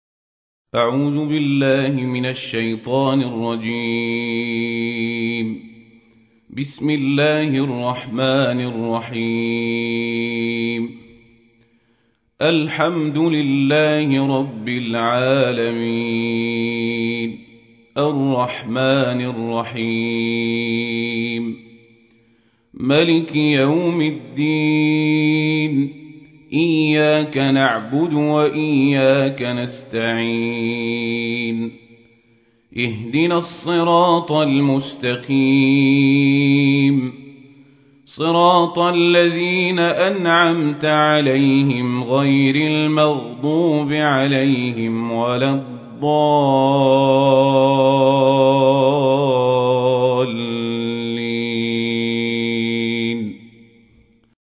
تحميل : 1. سورة الفاتحة / القارئ القزابري / القرآن الكريم / موقع يا حسين